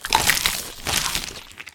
Demon_consume.ogg